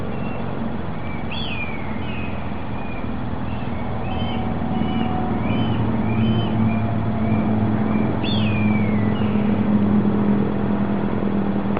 Audio clip of Red Tailed Hawk at Spencer Interchange construction site